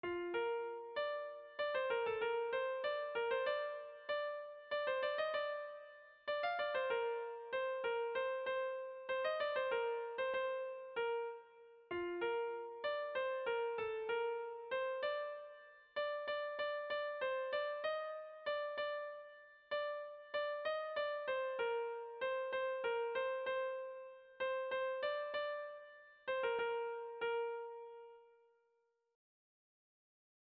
Erromantzea
AABA2B2